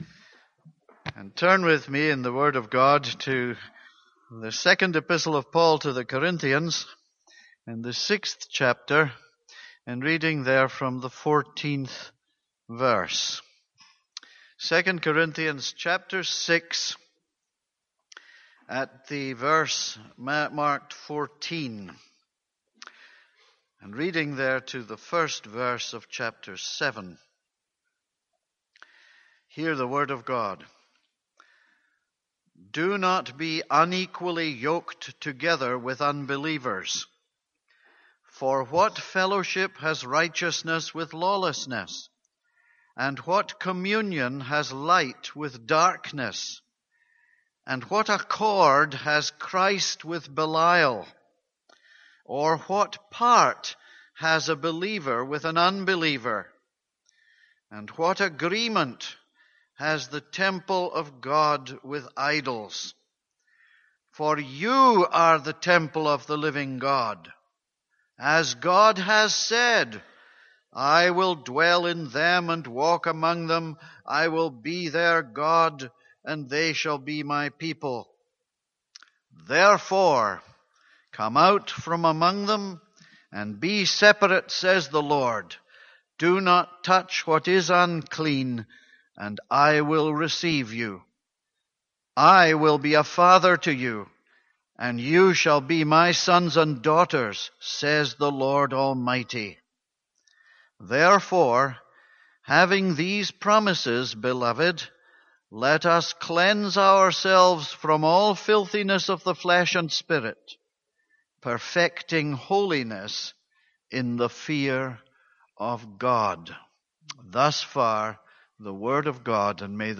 This is a sermon on 2 Corinthians 6:14-7:1.